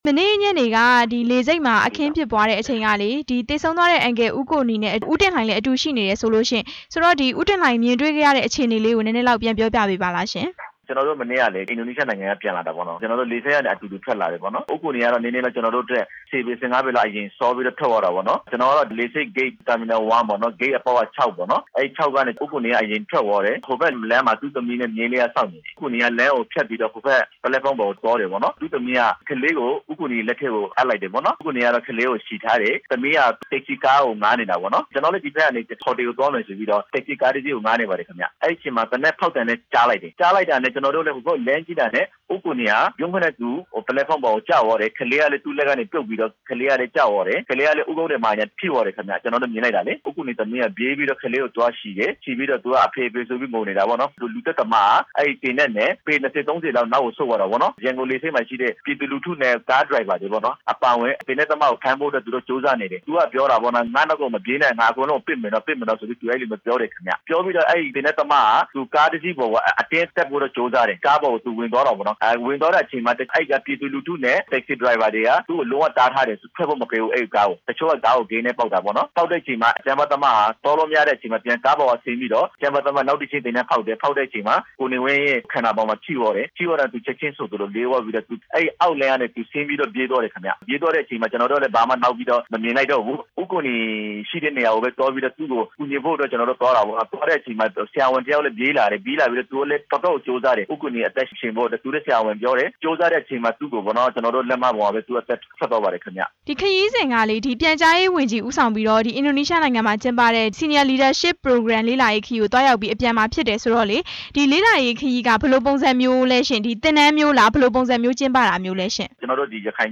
ရှေ့နေ ဦးကိုနီ လုပ်ကြံခံရမှု မေးမြန်းချက်